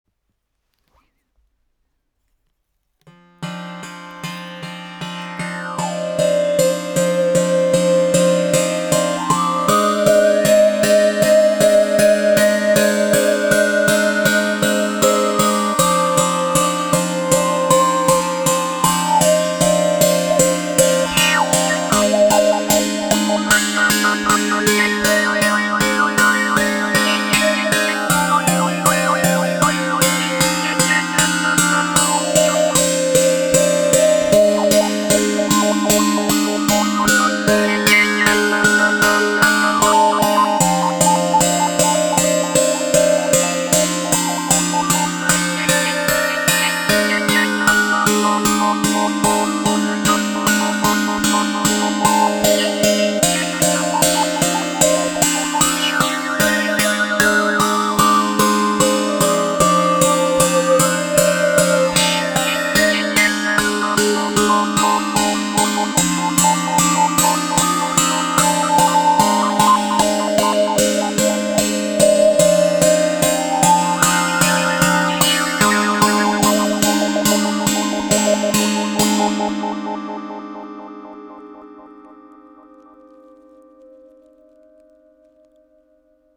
COSMICBOW 5 CORDES avec cuillère harmonique
Le son est très chaud et attrayant…entrainant.
Les sons proposés ici sont réalisés sans effet.
La baguette sera utilisée pour percuter les cordes, l’une, l’autre ou toutes.